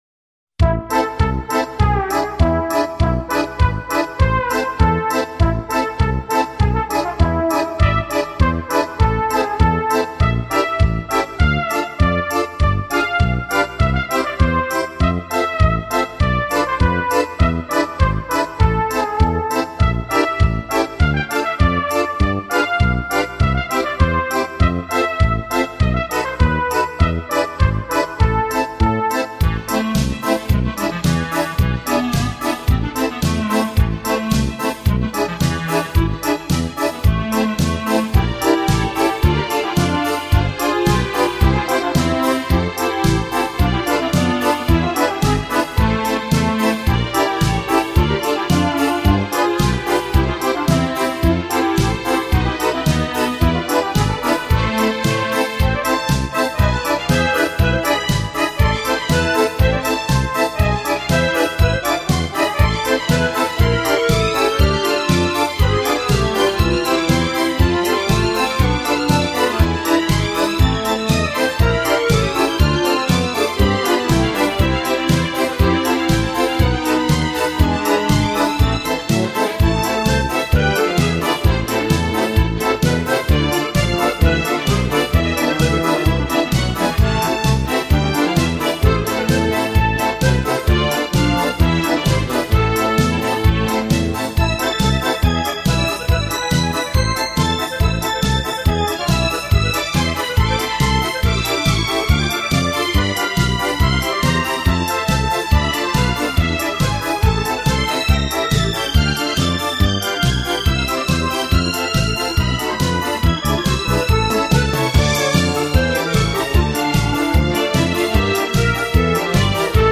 танцевальная песня
Танцы разных стилей